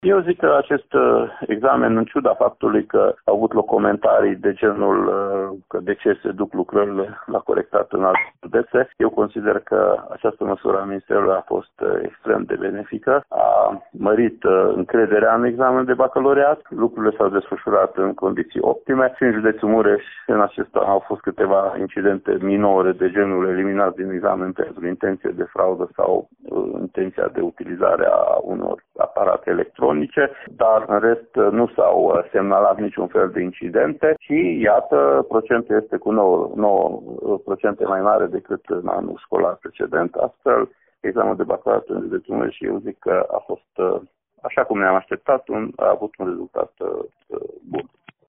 Inspectorul şcolar general al județului Mureș, Ştefan Someşan.